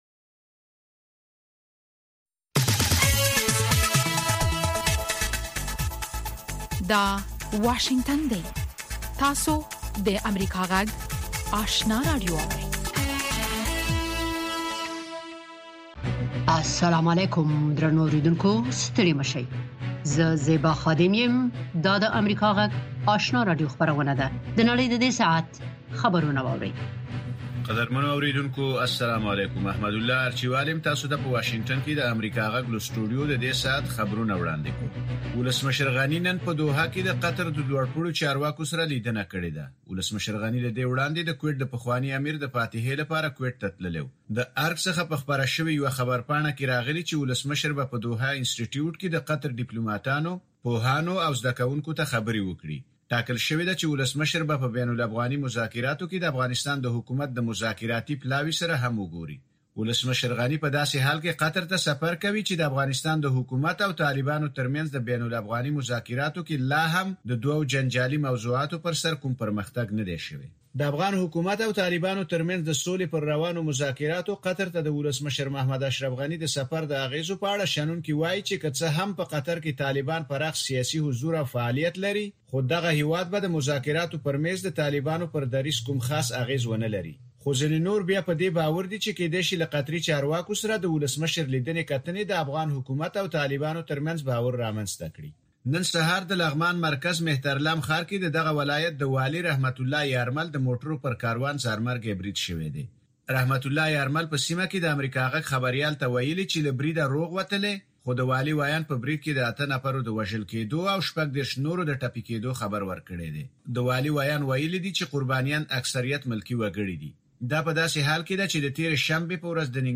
لومړۍ ماښامنۍ خبري خپرونه